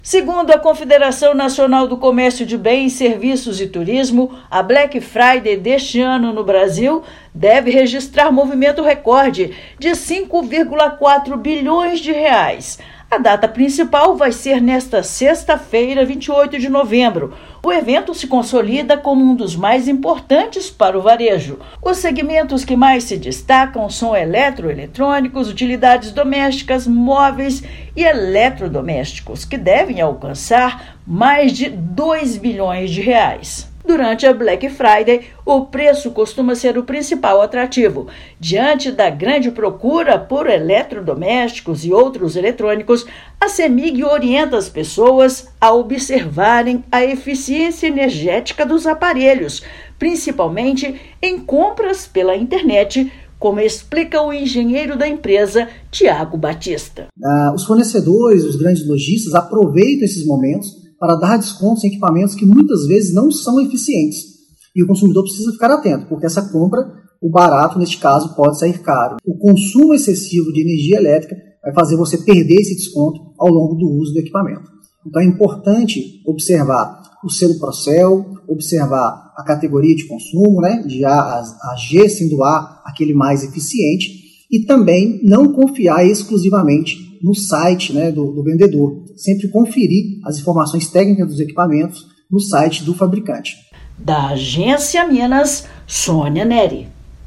[RÁDIO] Black Friday: Cemig alerta que produto ineficiente pode gerar custo na conta de energia
Verificar a eficiência energética do equipamento é fundamental para evitar despesas adicionais na fatura. Ouça matéria de rádio.